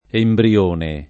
[ embri- 1 ne ]